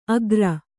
♪ agra